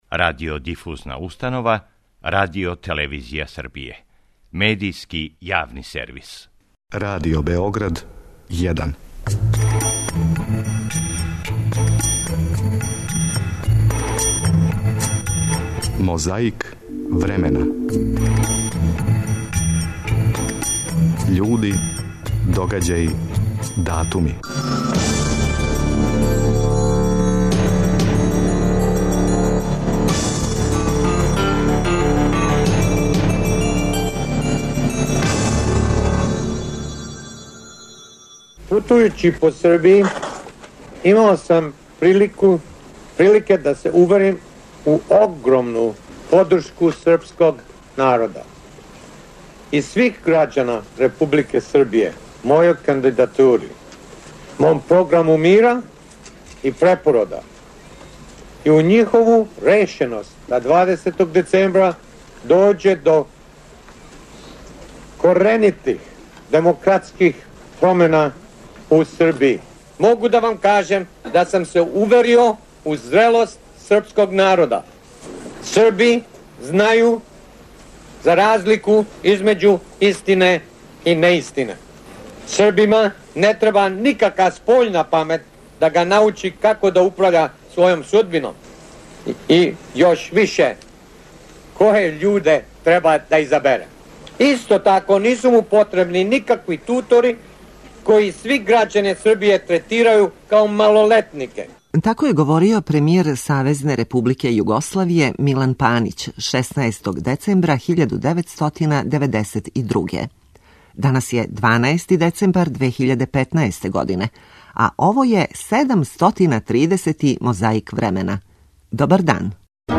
О томе говори тонски запис његовог и још неких говора са митинга ДЕПОС-а одржаног 17. децембра 1992. године.
Уз френетичан аплауз и скандирање, друг Тито се обратио присутнима на заједничком конгресу СКОЈ-а и Народне омладине.